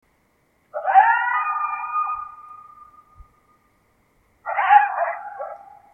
Dogs Howling Yelping Distance Far 05 Coyote Bouton sonore